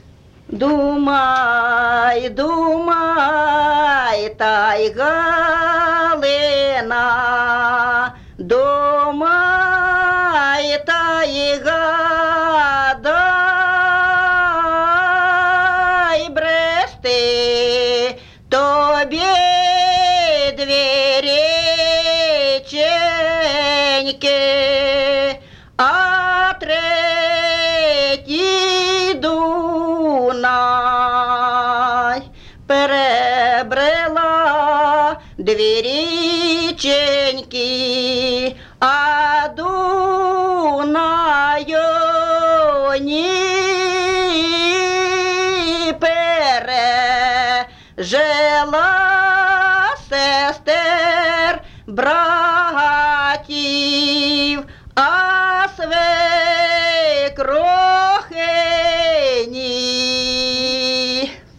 ЖанрВесільні
Місце записус. Ізюмське, Борівський район, Харківська обл., Україна, Слобожанщина